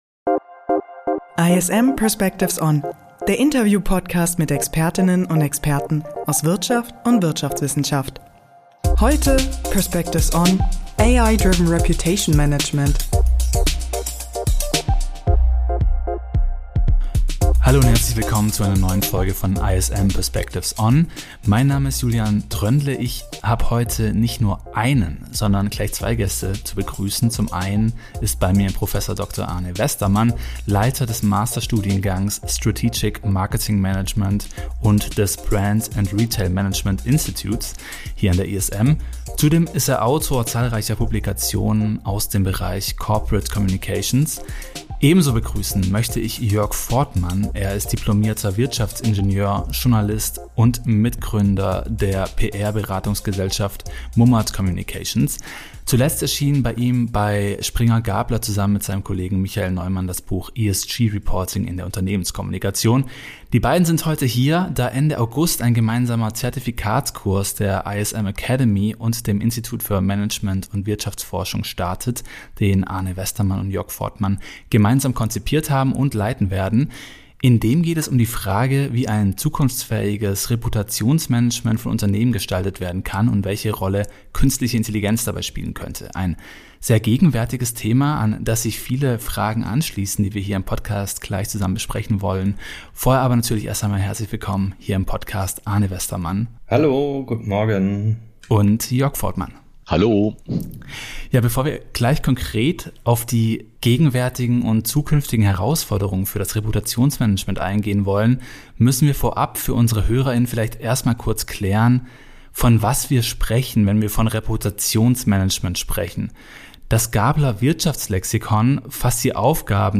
Außerdem geht es in unserem Gespräch um die Relevanz von Reputationsrankings, den Zusammenhang von Reputation und Brand Value sowie um Möglichkeiten eines KI-gestützten Krisenmonitorings.